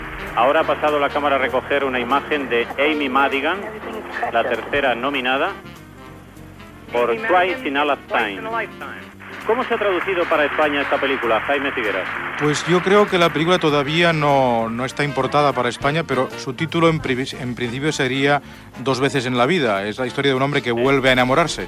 Transmissió, des del Dorothy Chandler Pavilion de Los Angeles, de la cerimònia de lliurament dels premis Oscar de cinema.
Careta del programa, presentació i primeres intervencions de l'equip.